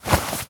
foley_object_push_pull_move_02.wav